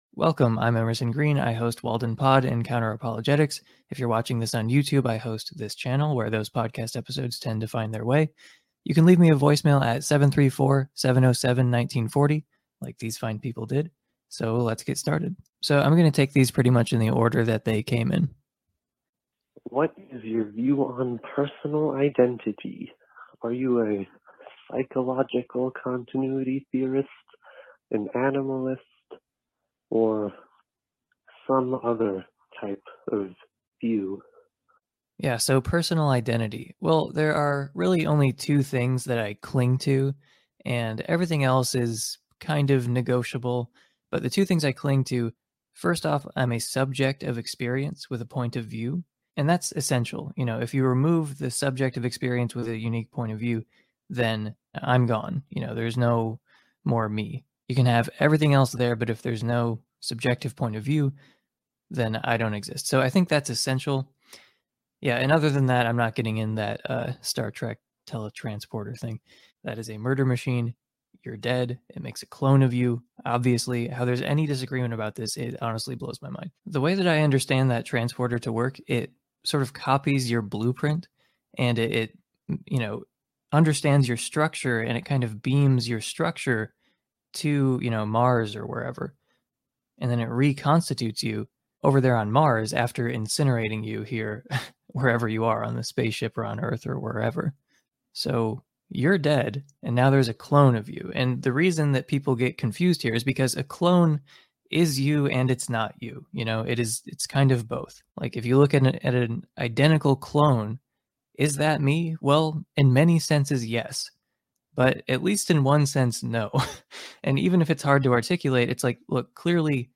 Call In Show #1 - Personal Identity, Idealism